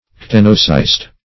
ctenocyst.mp3